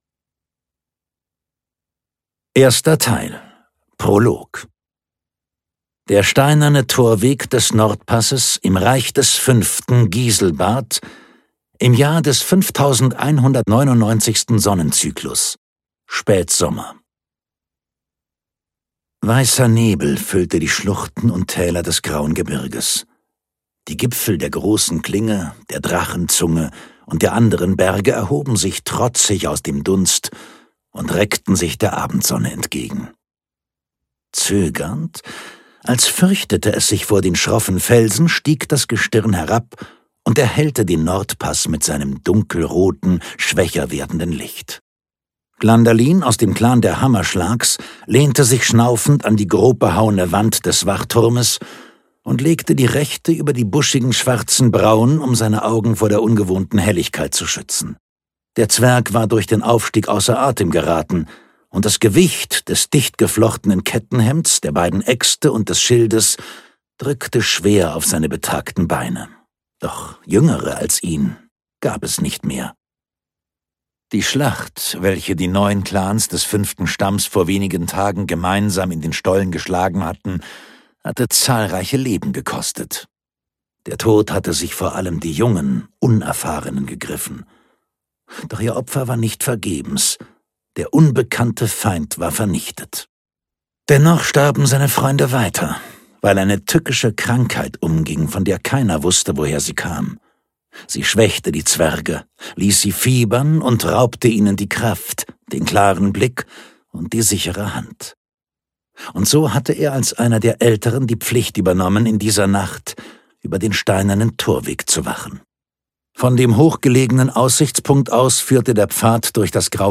Die Zwerge (Die Zwerge 1) - Markus Heitz - Hörbuch